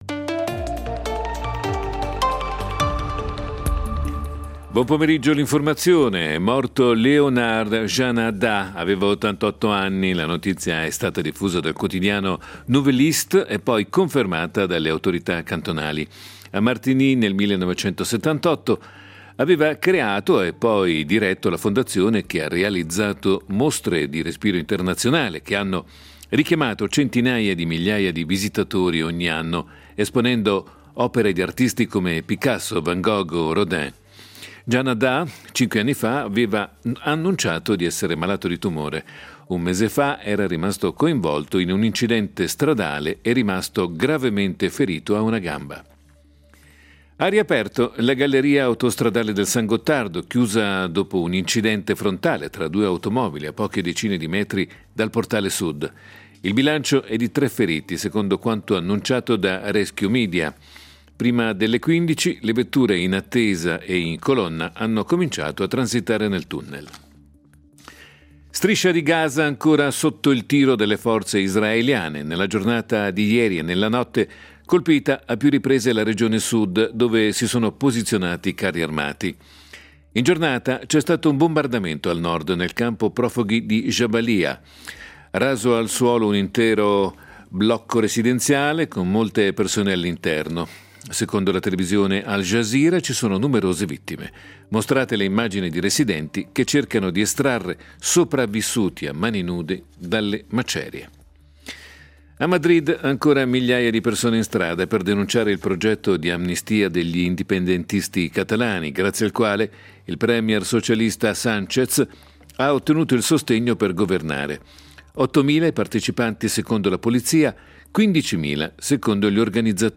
Notiziario delle 16:00 del 03.12.2023